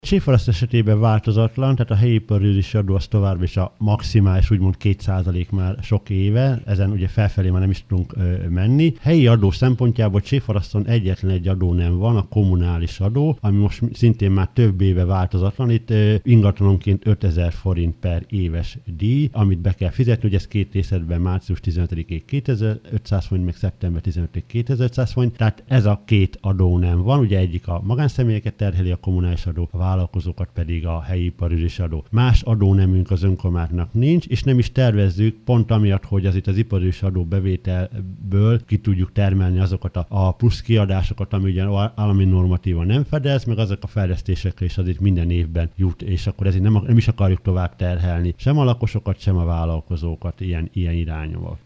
Pulisch József polgármestert hallják.